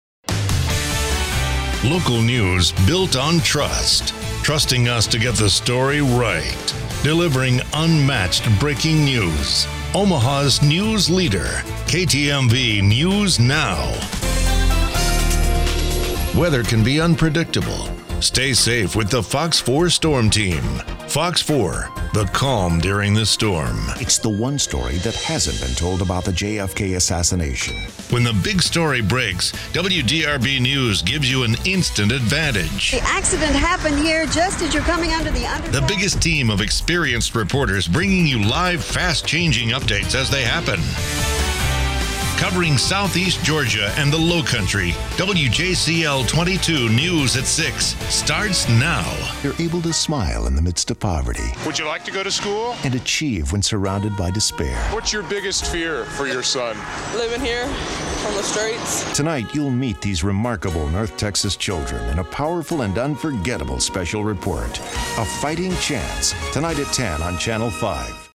Smooth, warm, conversational, authentic and inviting, yet capably diverse
Authentic Texan
Middle Aged
I have a great home studio with Neumann mic, Pro Tools, isolated vocal booth and Source Connect!!